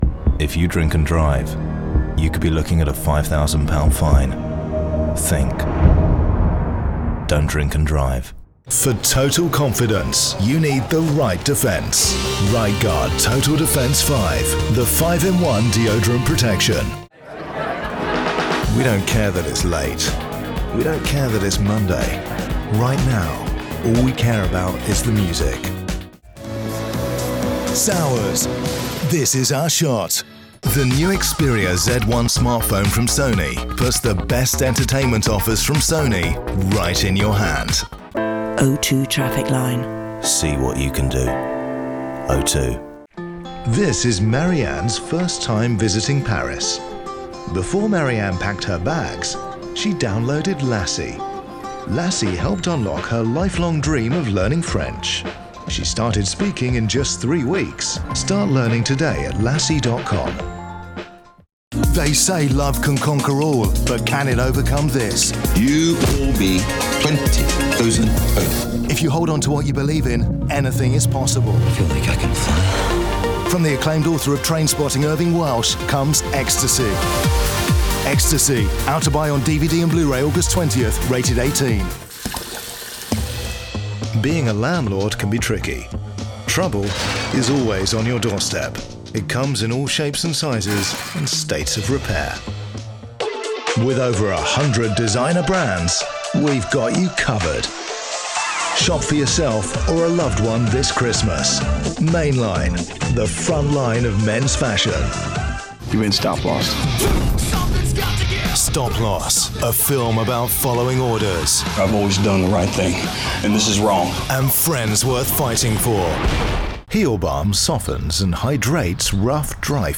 Commercial Showreel
Male
Neutral British
Cockney
Confident
Cool
Gravelly
Gravitas